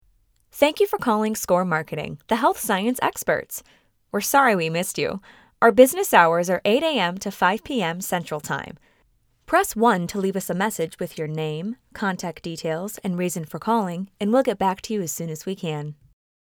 Female
Yng Adult (18-29), Adult (30-50)
Phone Greetings / On Hold
Marketing Firm Voicemail
1120SCORR_After_Hours_Voicemail.mp3